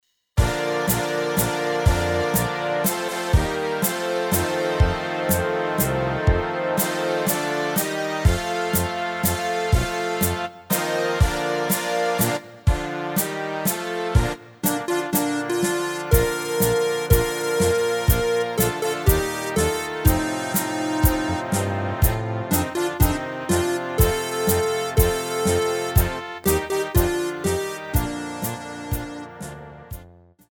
Rubrika: Národní, lidové, dechovka